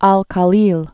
(äl kä-lēl, ä-)